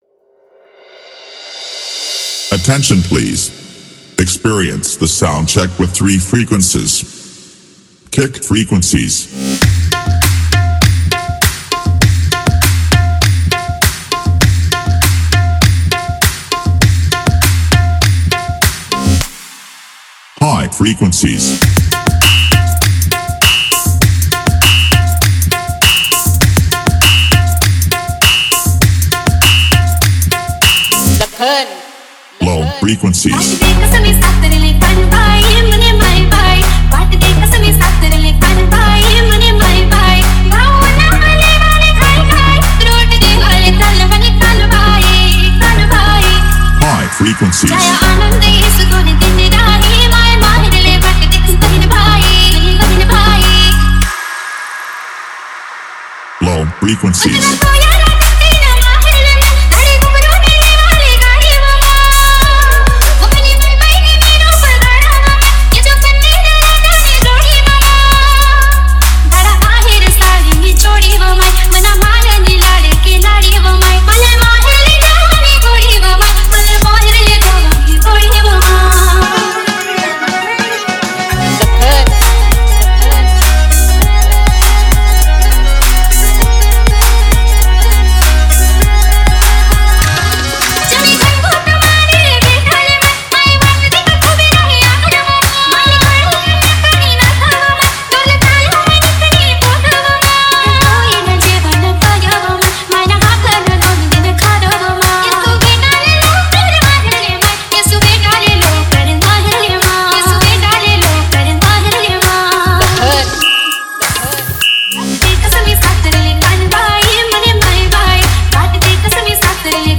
#1 New Marathi Dj Song Album Latest Remix Releases
soundcheck